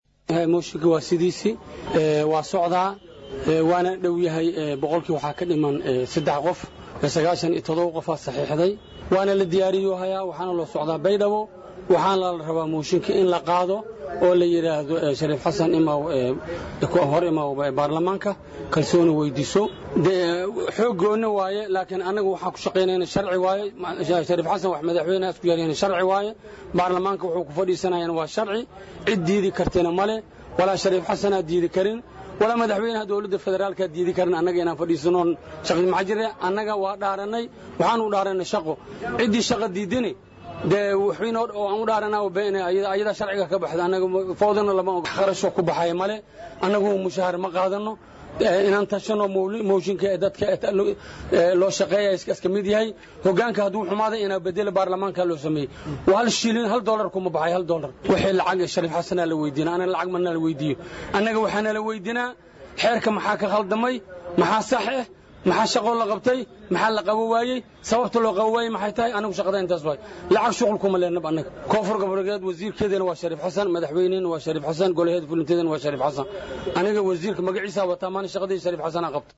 Muqdisho ( INO)-Wasiirka Diinta iyo Awqaafta Maamulka Koofur Galbeed Soomaaliya Axmed Maxamed Barre ( Macallin Barre) ayaa Ka warbixiyay Meesha uu marayo mooshin ka dhan ah Madaxweynaha Maamulka Koofur Galbeed Shariif Xasan Sheekh Aadan.